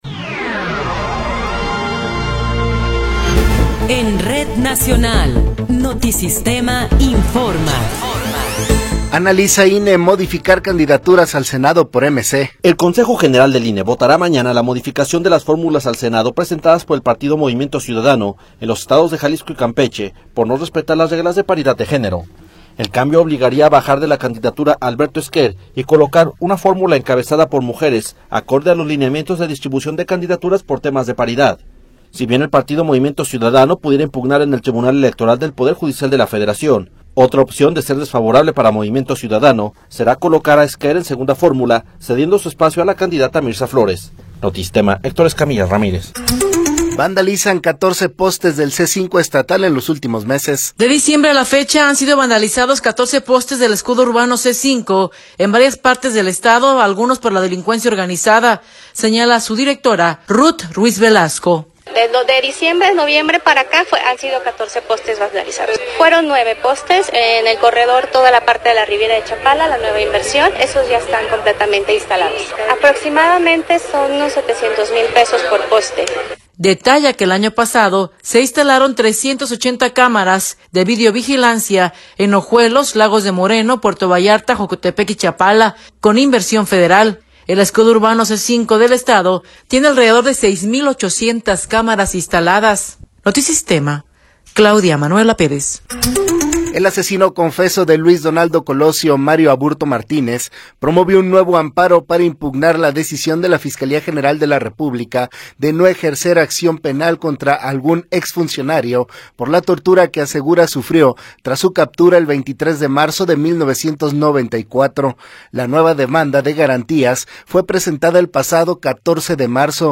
Noticiero 17 hrs. – 20 de Marzo de 2024
Resumen informativo Notisistema, la mejor y más completa información cada hora en la hora.